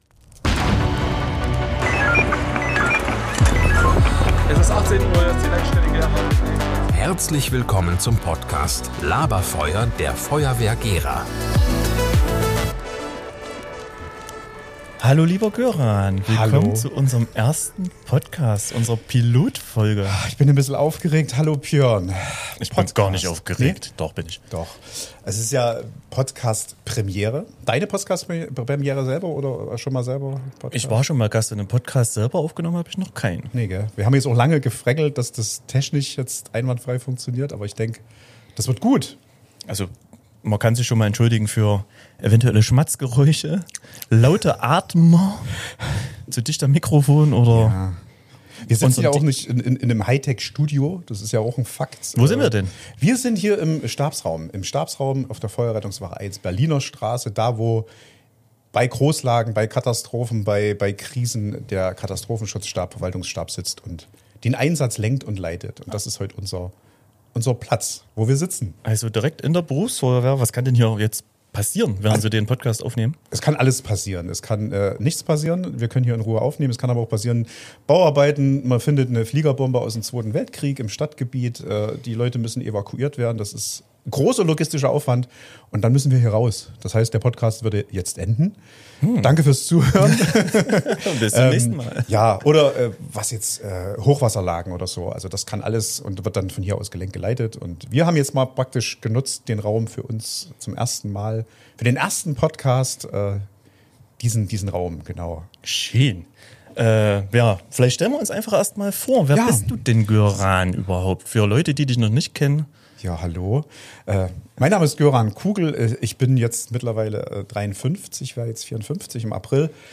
Wir feiern unseren Einstand als Podcaster und nehmen euch mit an den Ursprung des LABER-FEUERS: mitten in den Stabsraum der Feuerwehr Gera.
In dieser Pilotfolge sprechen wir offen über unsere Motivation, den Wandel in der Medienlandschaft und warum ein Podcast genau jetzt das richtige Format für die Feuerwehr ist. Wir stellen uns als Hosts vor, teilen persönliche Geschichten und geben euch einen Ausblick auf spannende Gäste, Rubriken und echte Einsatzmomente.